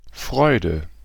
Ääntäminen
Synonyymit spice relish tang gusto desire rind peel zestiness Ääntäminen US : IPA : [ˈzɛst] Haettu sana löytyi näillä lähdekielillä: englanti Käännös Ääninäyte Substantiivit 1.